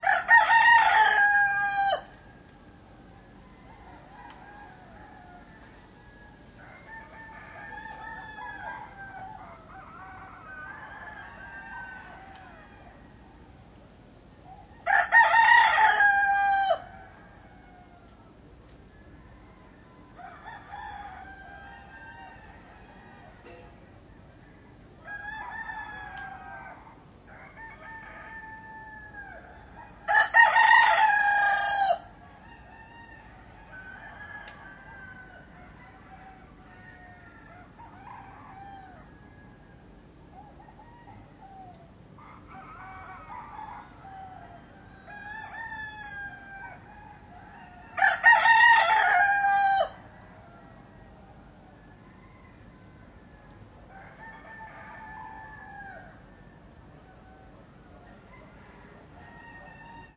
描述：星期天，一些盲人去Boyer唱歌和拳击最好的海地歌曲。
声道立体声